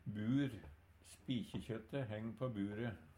bur - Numedalsmål (en-US)